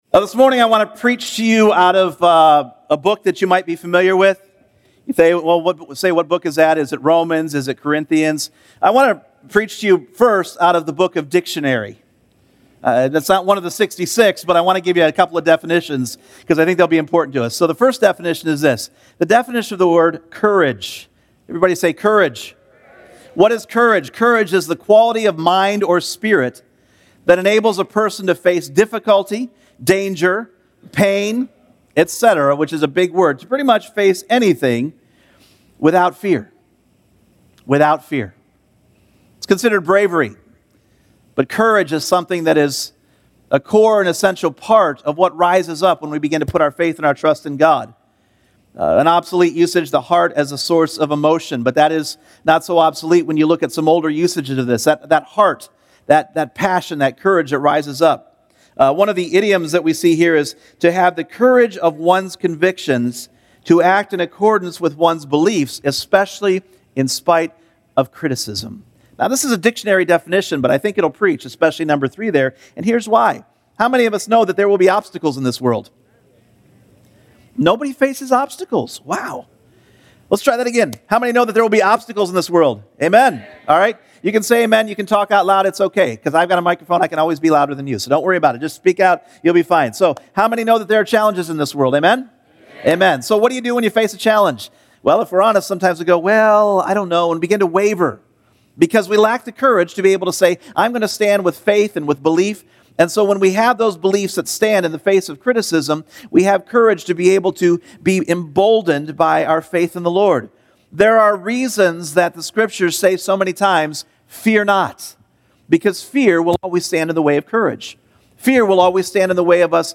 Individual Messages Service Type: Sunday Morning Courage.